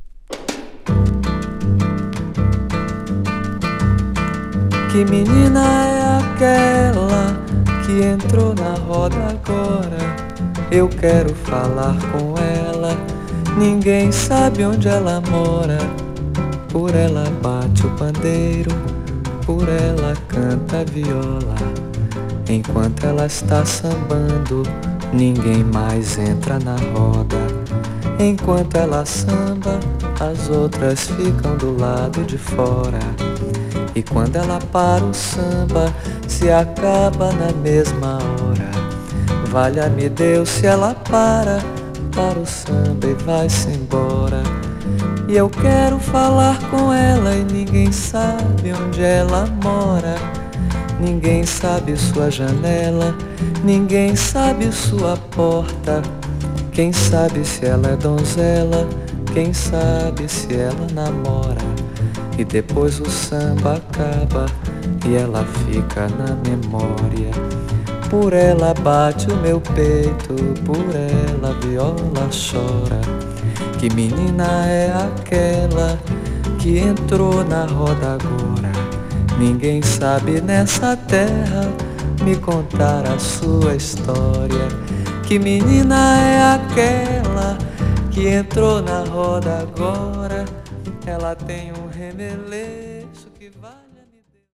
陰影のあるコード感とオーケストレーションに彩られた繊細で美しい風景。